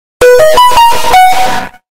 Notification Loudd